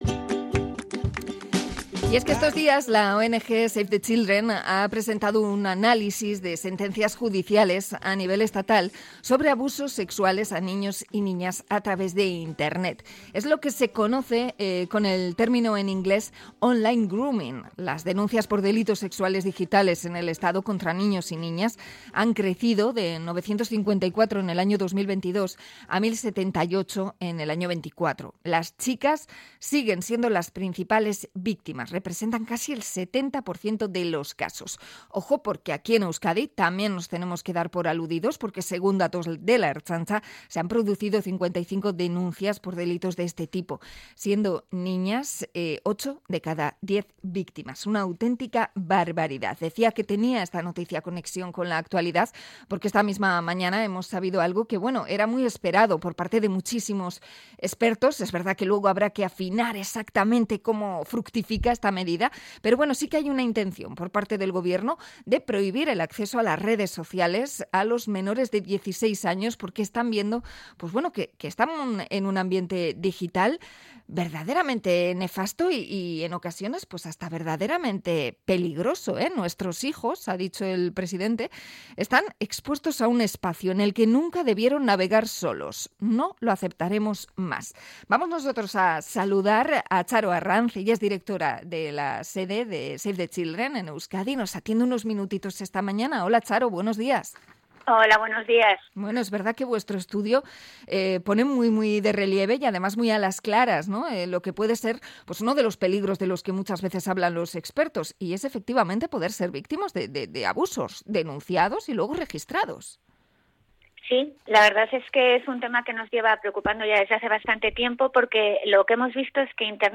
Entrevista a Save the Children sobre el grooming online y acoso sexual a menores